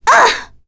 Ophelia-Vox_Damage_02.wav